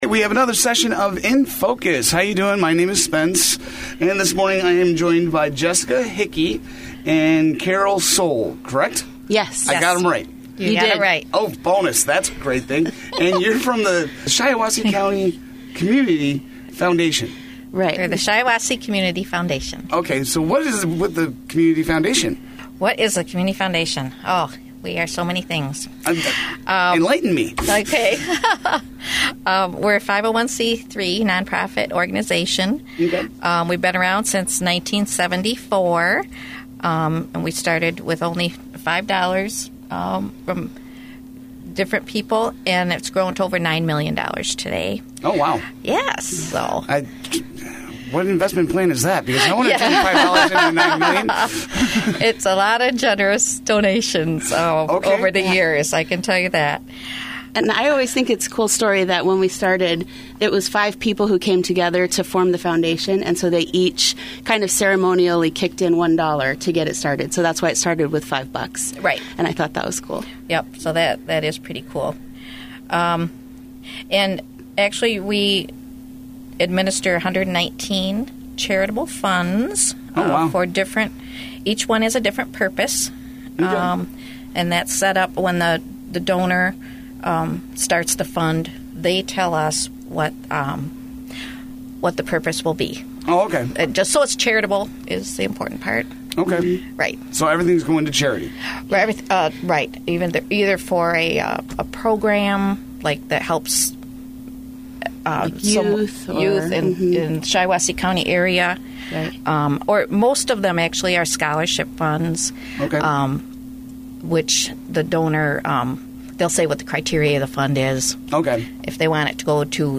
The radio interview–which aired on Sunday, June 17–can be heard by clicking the link below.